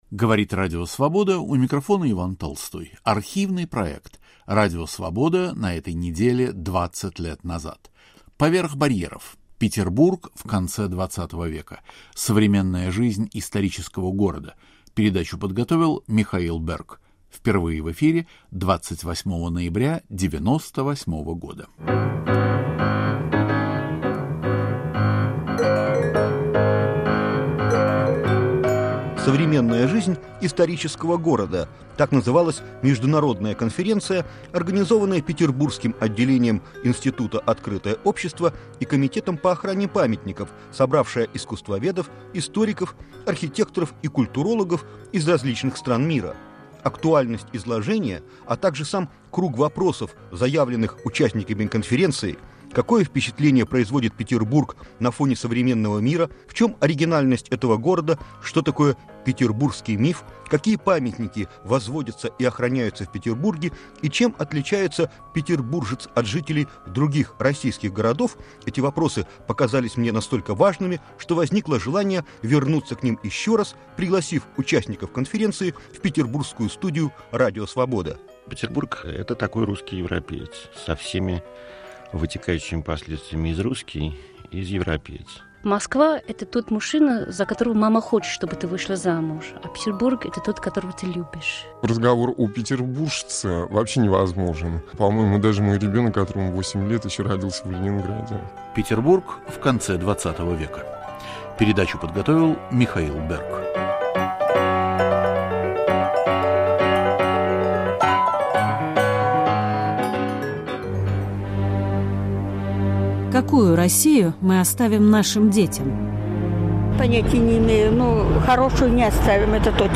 Чем отличается современный Петербург от других городов России и мира? Как сделать город удобным для жителей и привлекательным для туристов, не исказив его облик? В студии Радио Свобода искусствоведы